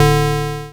RedButton.wav